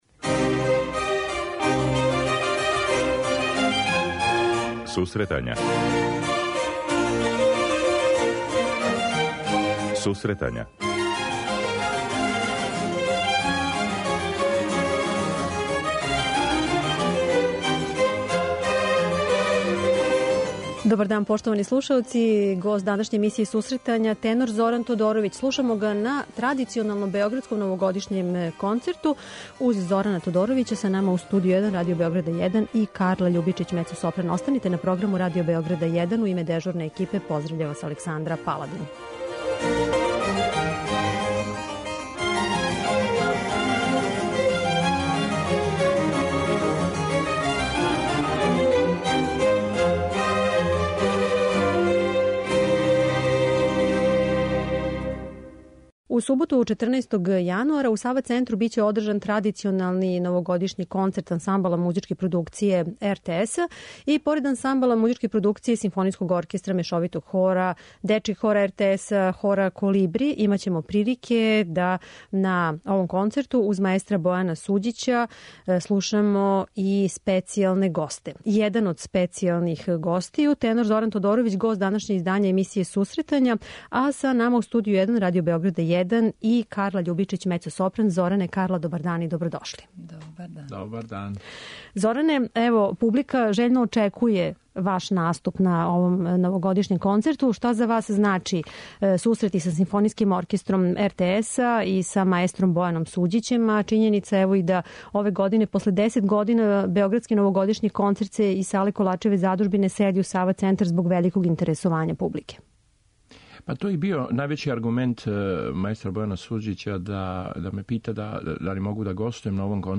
Десети по реду 'Београдски новогодишњи концерт' Музичке продукције РТС биће одржан у суботу, 14. јануара у Сава Центру. Као специјални гост наступиће један од највећих светских тенора, Зоран Тодоровић, који је и гост данашње емисије.
Са њим ће у студију бити и млади мецосопран